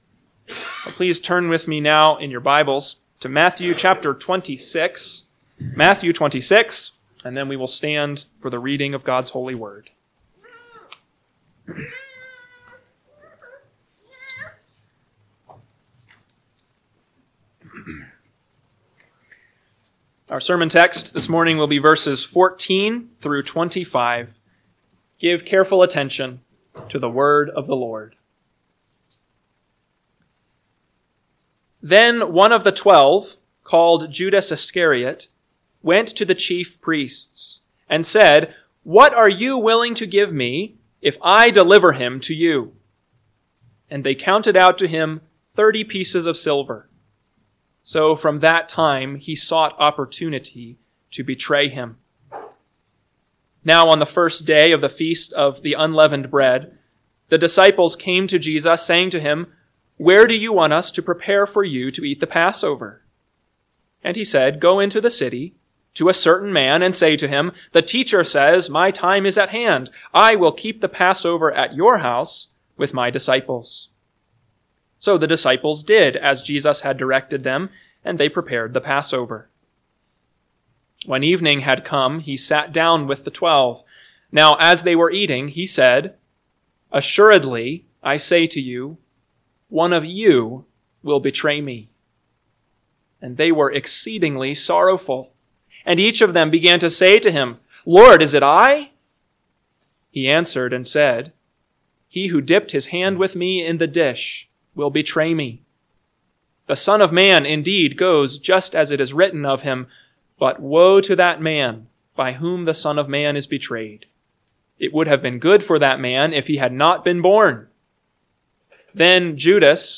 AM Sermon – 2/16/2025 – Matthew 26:14-25 – Northwoods Sermons